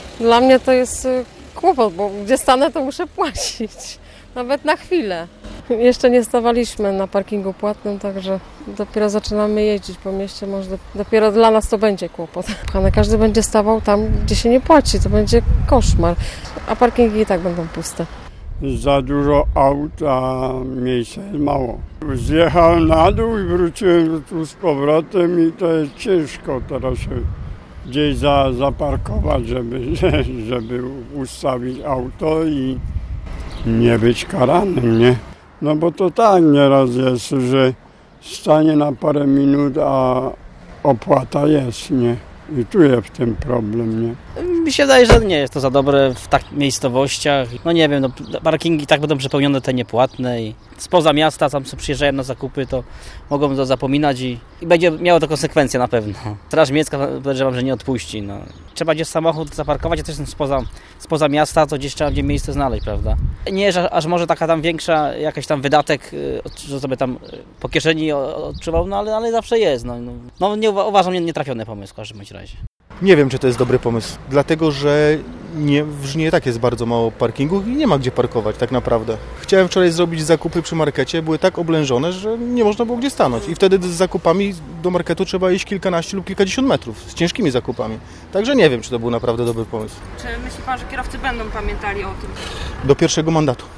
Zapytaliśmy jak kierowcy oceniają wprowadzone rozwiązanie ?
1_kierowcy.mp3